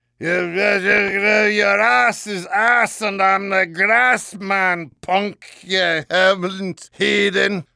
demoman_gibberish01-online-audio-converter.mp3